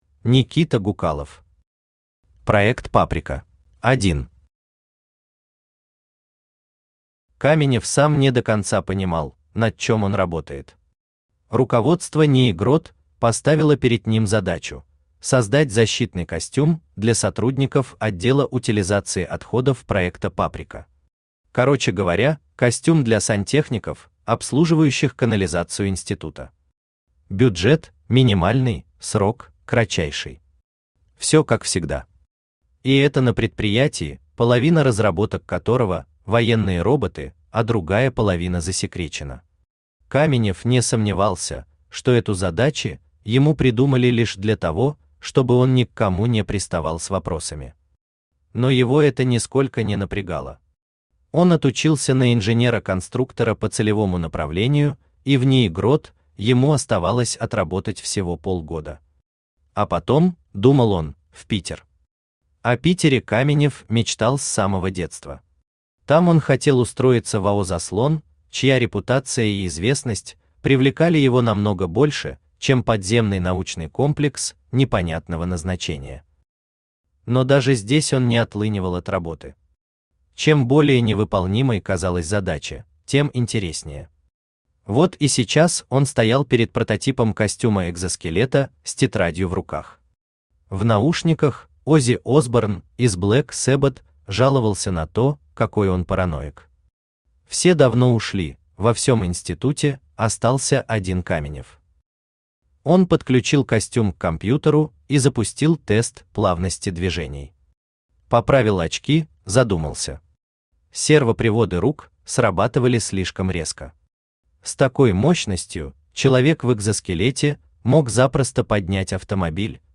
Aудиокнига Проект «Паприка» Автор Никита Гукалов Читает аудиокнигу Авточтец ЛитРес.